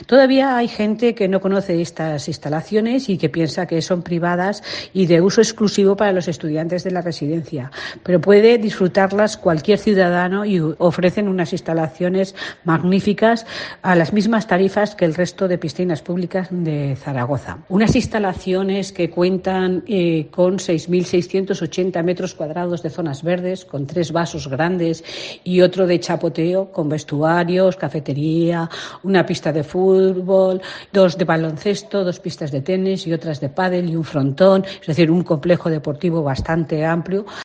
La diputada delegada de la Residencia Pignatelli, Pilar Mustieles, explica cómo es la piscina de este centro.